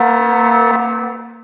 laser_siren_single.wav